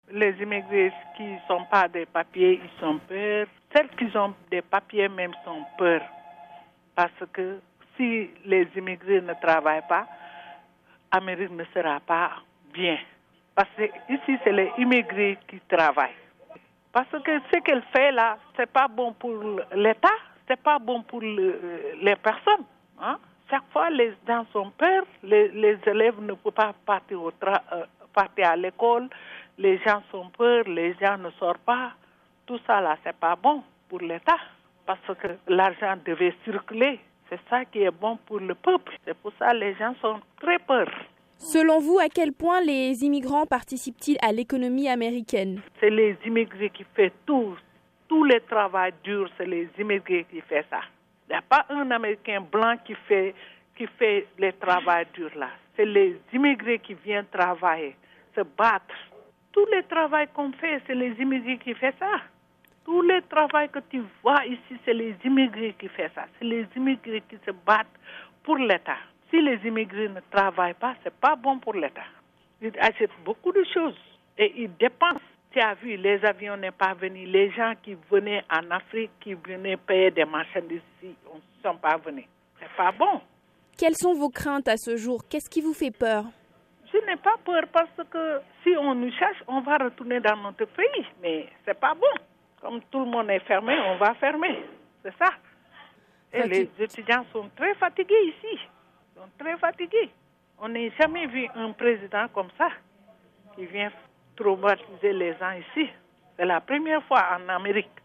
Une restauratrice sénégalaise de New York jointe par